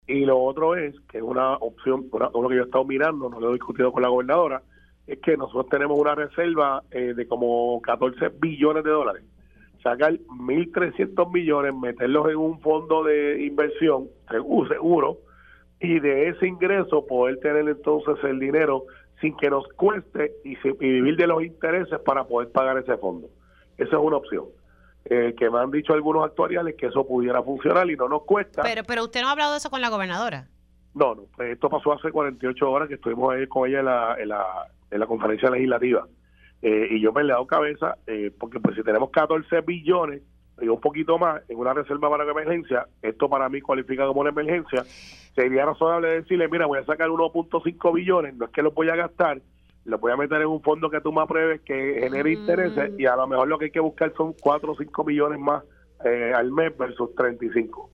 El vicepresidente del Senado, Carmelo Ríos indicó en Pega’os en la Mañana que una opción para resolver la crisis de las pensiones de los jubilados de la Autoridad de Energía Eléctrica (AEE) podría ser crear un fondo de inversión con dinero de la reserva.
406-CARMELO-RIOS-VICEPRESIDENTE-SENADO-PROPONER-CREAR-FONDO-DE-INVERSION-CON-DINERO-DE-LA-RESERVA.mp3